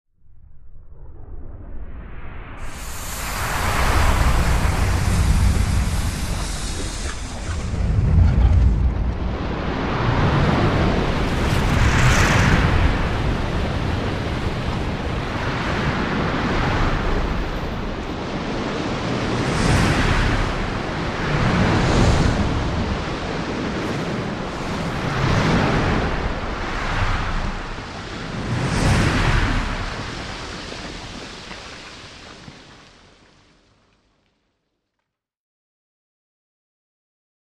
Landslide Or Avalanche Movement